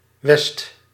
Ääntäminen
IPA: /ʋɛst/